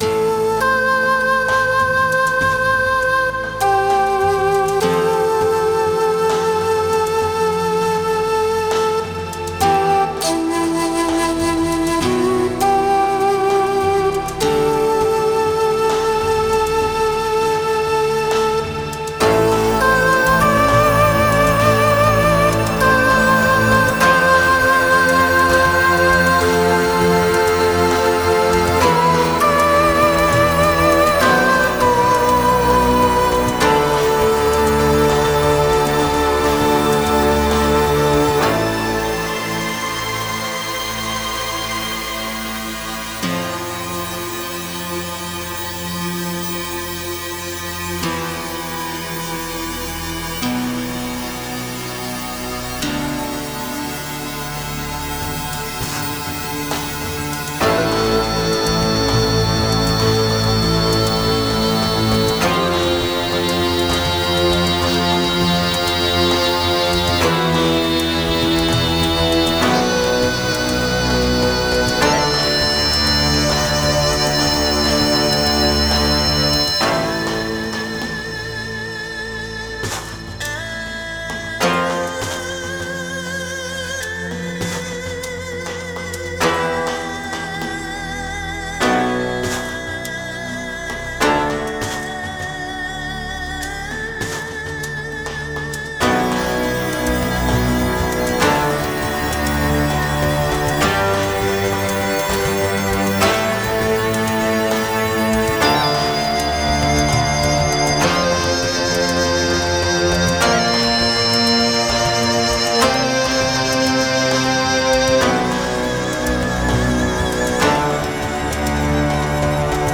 Ethnic Ambient
Такой отстранёно светлый с персидскими мотивами))
Да, тут только программинг (семлпы, синты, банки и пр.)